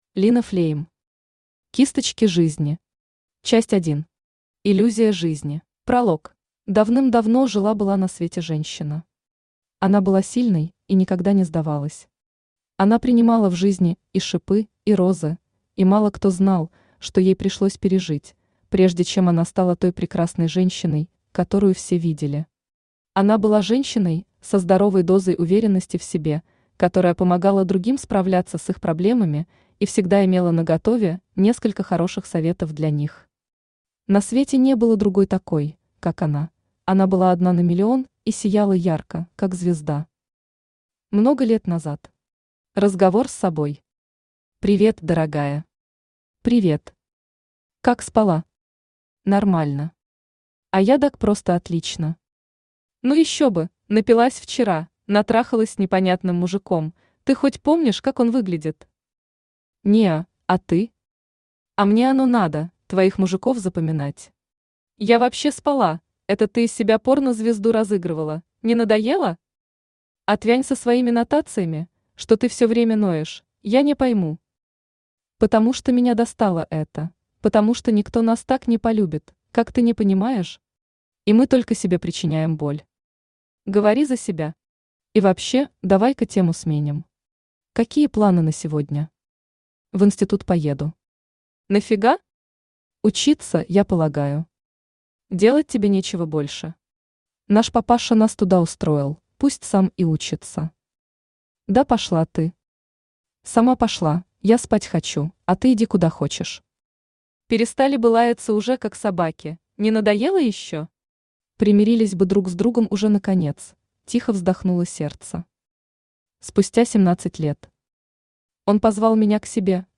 Аудиокнига Кисточки жизни. Часть 1. Иллюзия жизни | Библиотека аудиокниг
Иллюзия жизни Автор Лина Флейм Читает аудиокнигу Авточтец ЛитРес.